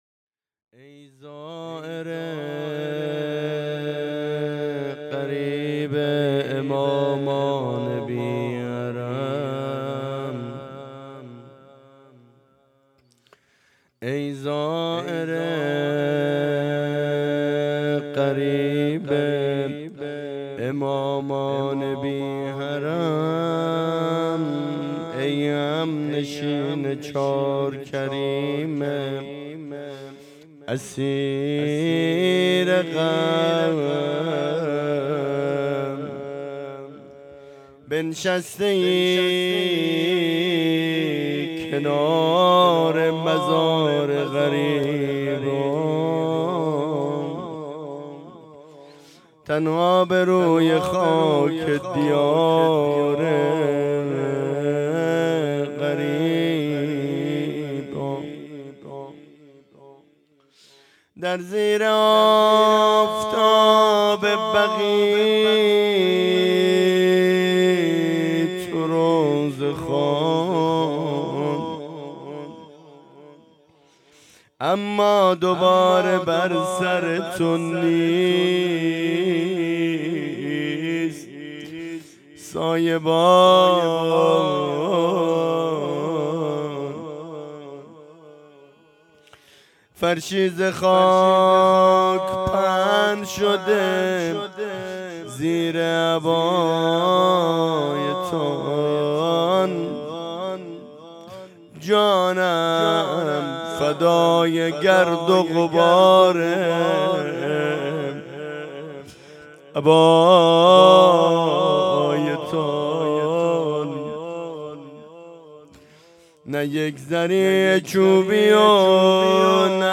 هیئت رایة الزهرا سلام الله علیها یزد